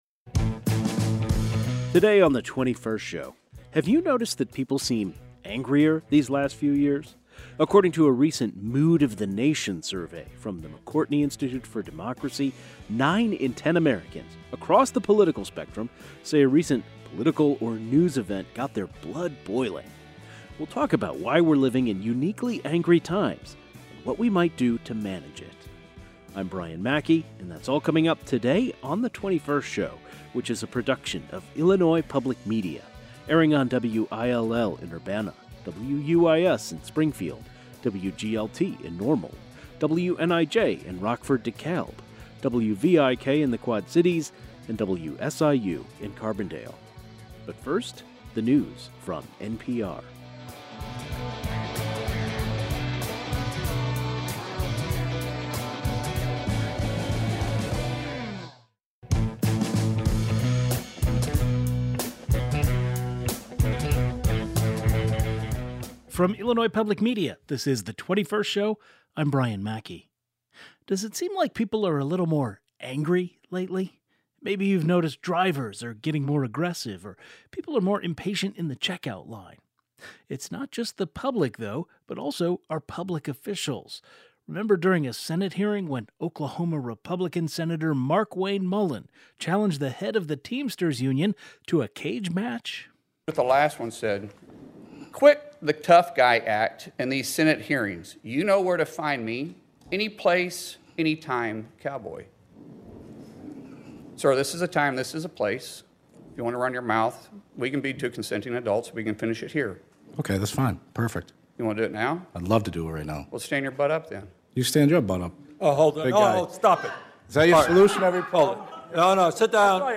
The 21st Show is Illinois' statewide weekday public radio talk show, connecting Illinois and bringing you the news, culture, and stories that matter to the 21st state.
According to the Mood of The Nation Survey from American Public Media and the McCourtney Institute for Democracy, nine in ten Americans can name a recent news event or something about our country’s politics that made them mad. A sociologist, a clinical psychologist, and an author who has written about how politics relates to the anger epidemic join the program.